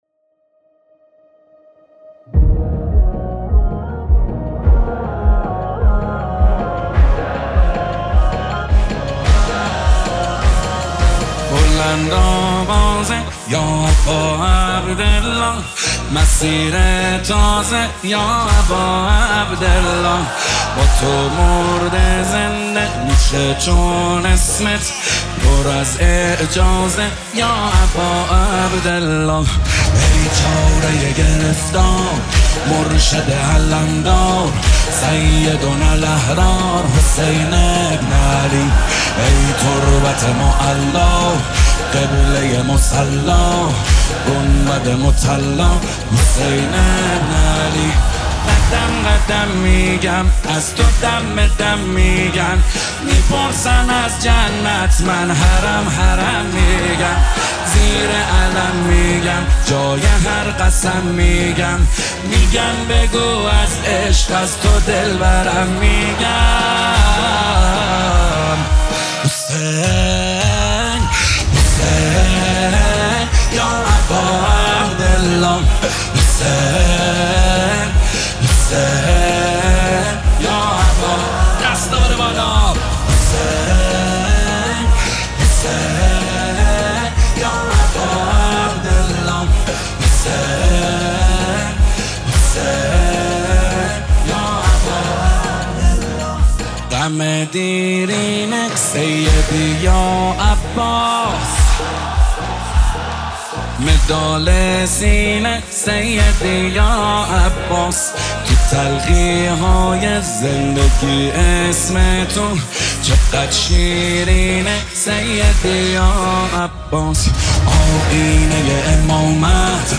استودیویی